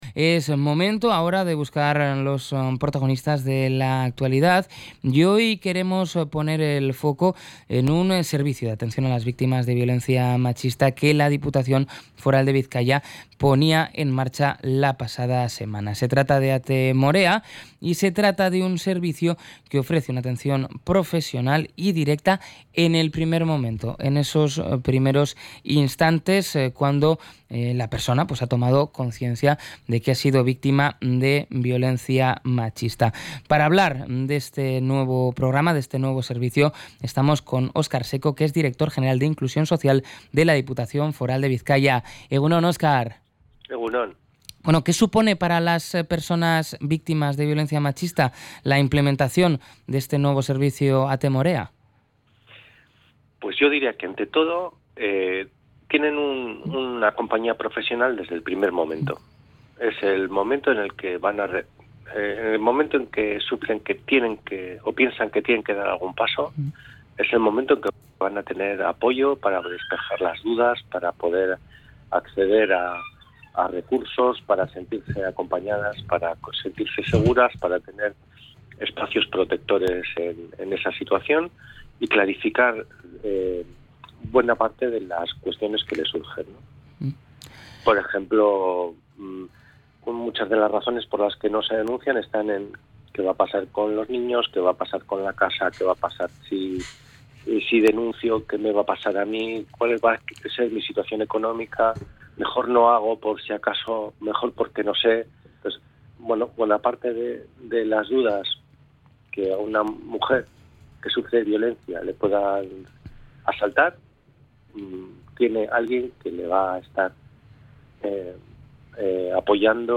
Entrevista-Oscar-Seco-Ate-Morea.mp3